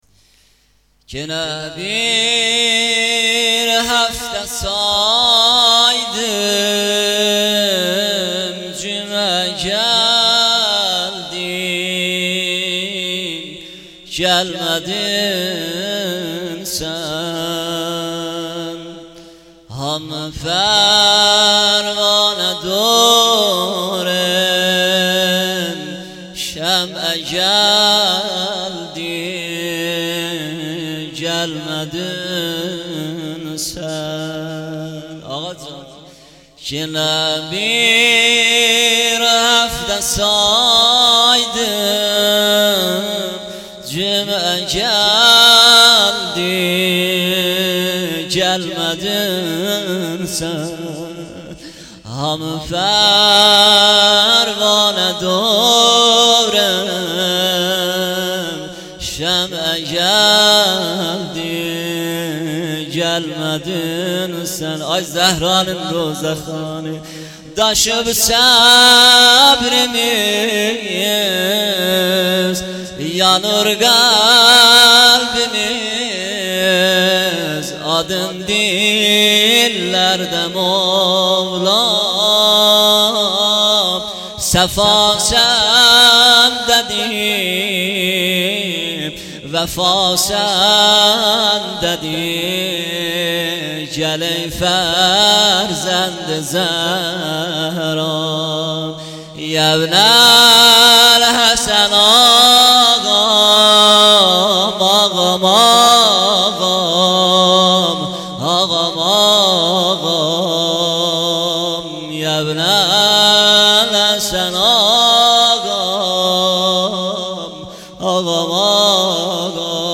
جلسه هفتگی
روضه حضرت زهرا